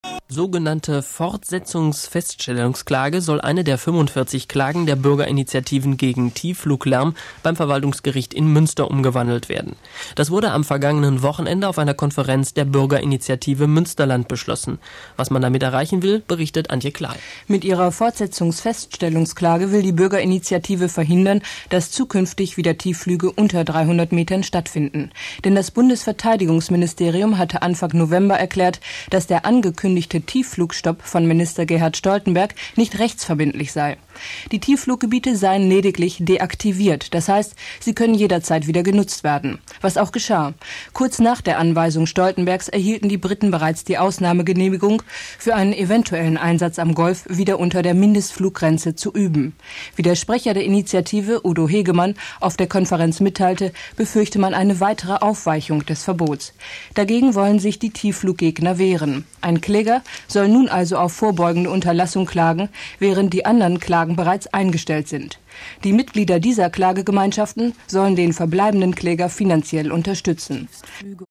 Radio-Beiträge von 1981 bis 2007
DIE FRIEDENSINITIATIVE IN RADIO-NACHRICHTEN